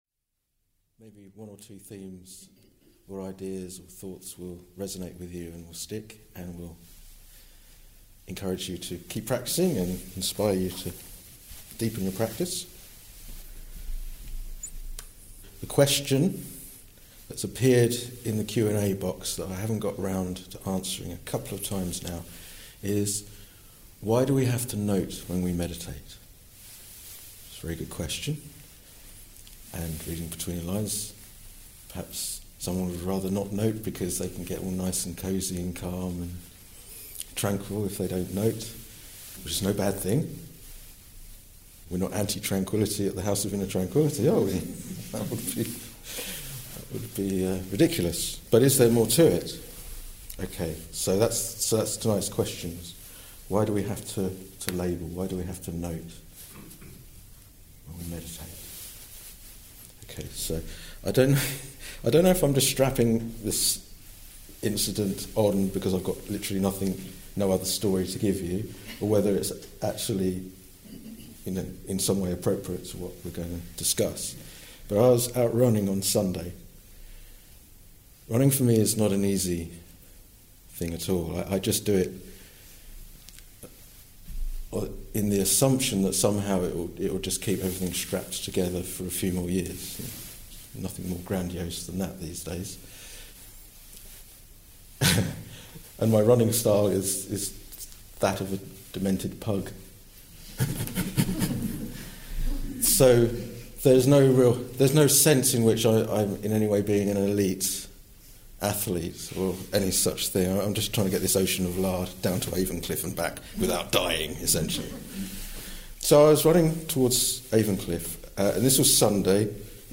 This talk was given in March 2018